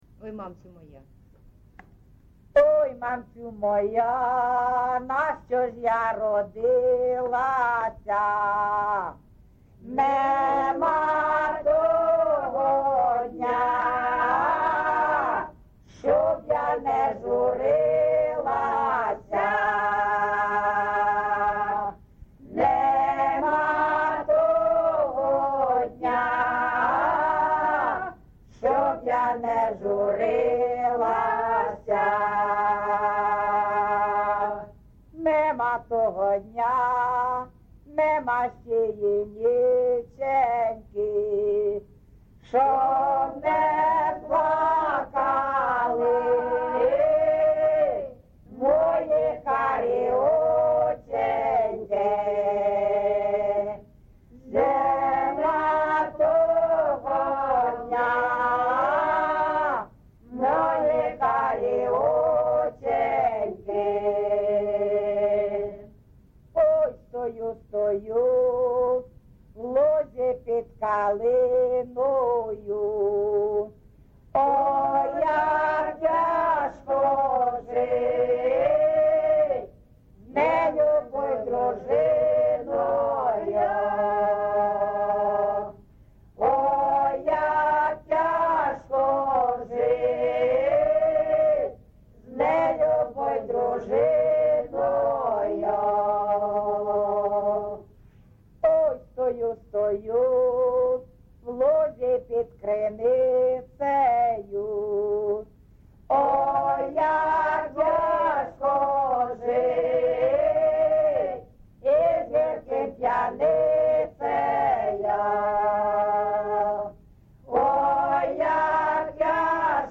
ЖанрПісні з особистого та родинного життя
Місце записус. Іскра (Андріївка-Клевцове), Великоновосілківський (Волноваський) район, Донецька обл., Україна, Слобожанщина